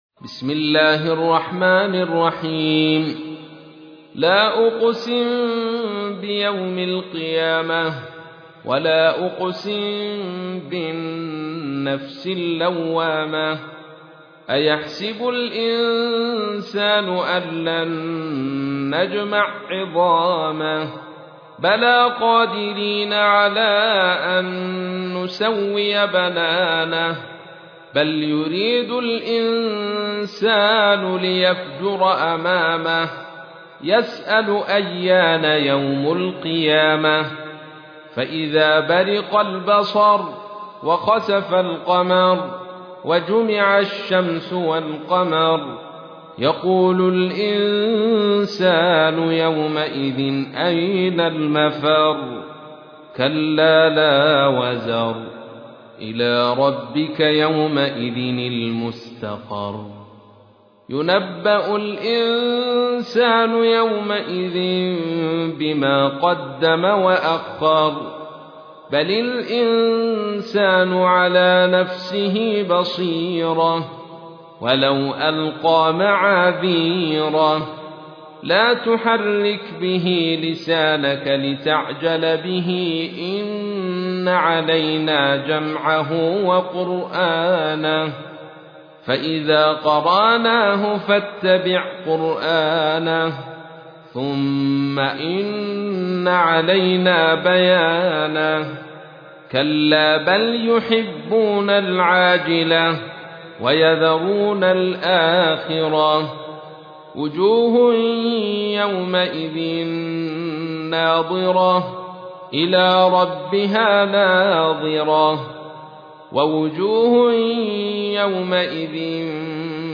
المصاحف - عبد الرشيد بن شيخ علي صوفي
المصحف المرتل - السوسي عن أبي عمرو